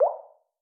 waterdrop-high.wav